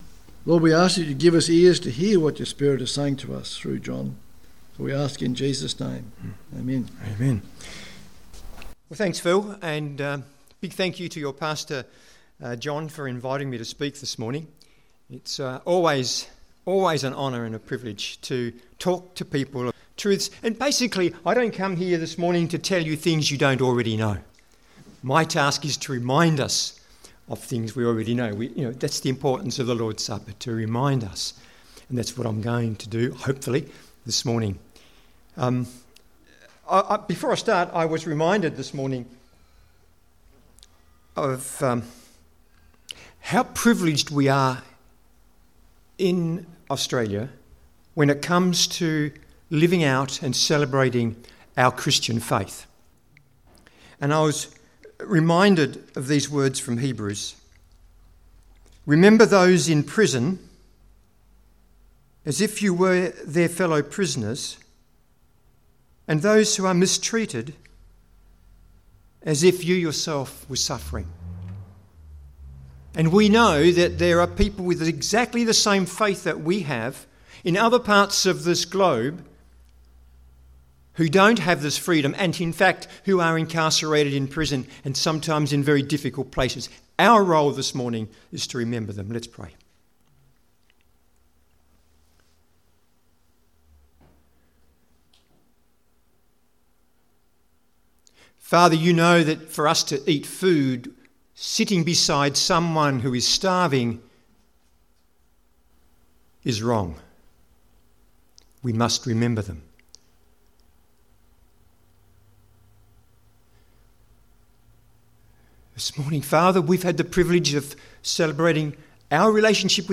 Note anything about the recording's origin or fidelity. Service Type: Pinjarra